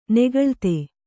♪ negaḷte